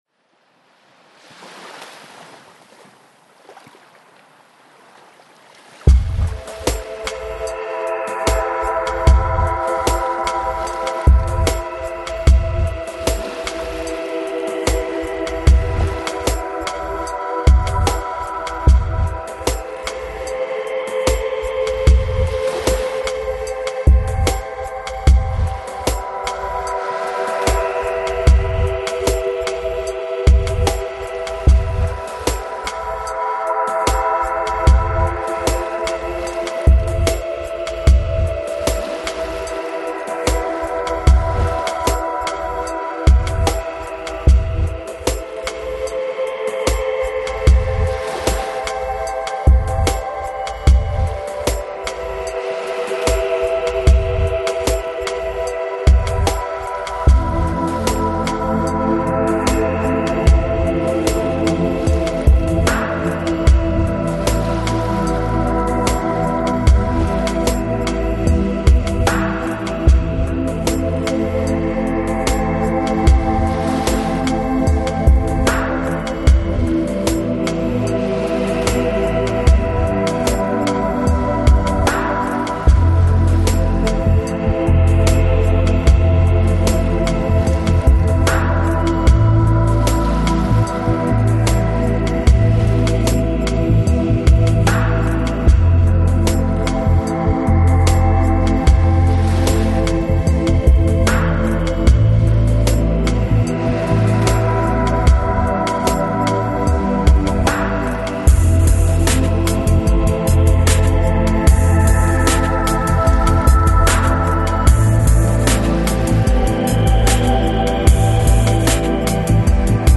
Electronic, Downtempo, Lounge, Chill Out